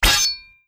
Melee Weapon Attack 16.wav